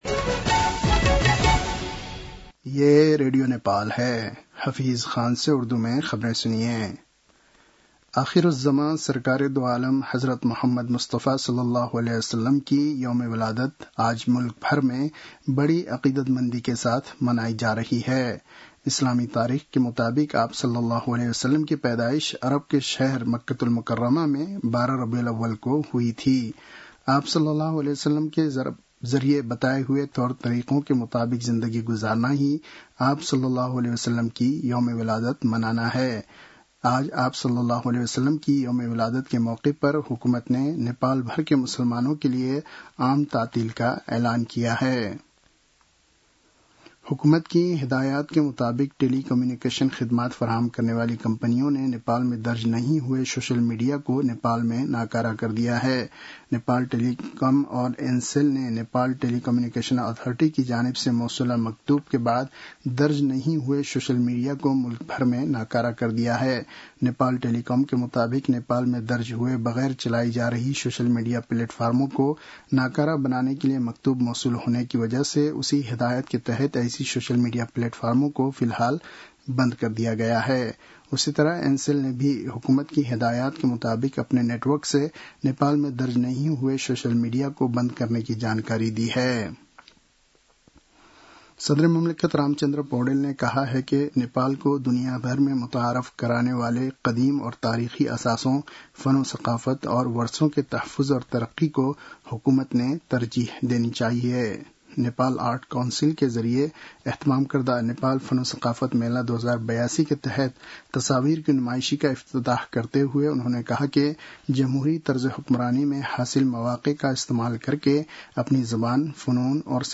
उर्दु भाषामा समाचार : २० भदौ , २०८२
Urdu-news-5-20.mp3